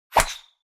sonido_bala.wav